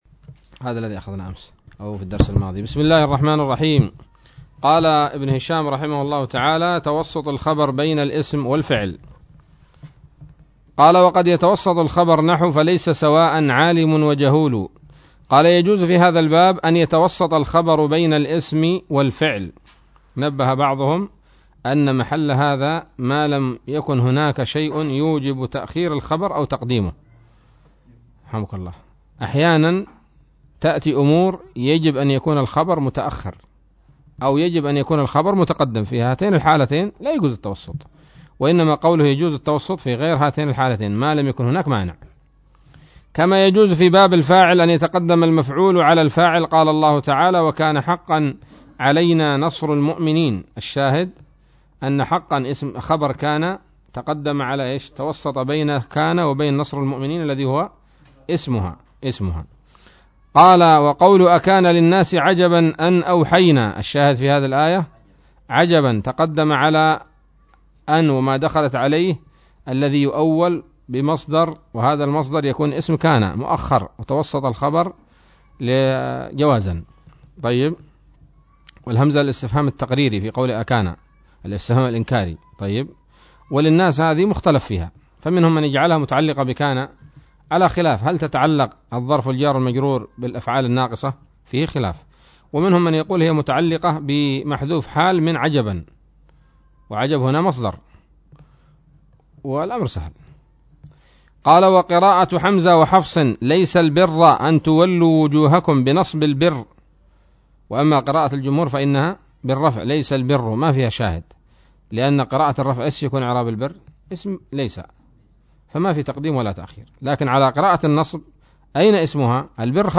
الدرس السابع والخمسون من شرح قطر الندى وبل الصدى